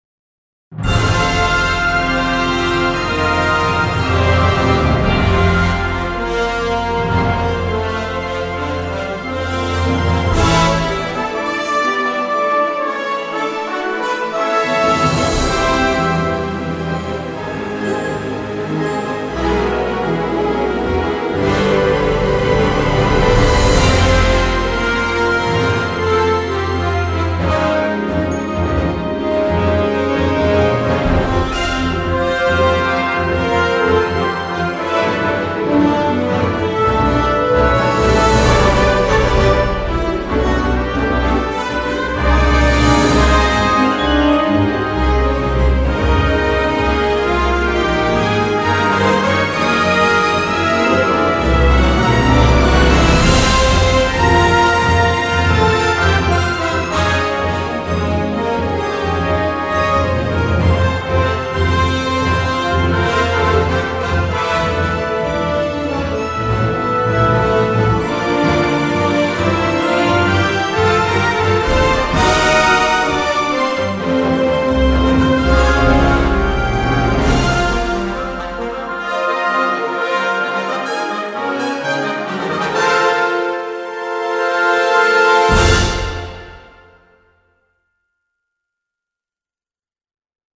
The tune has a very updated, motion-picture sound to it.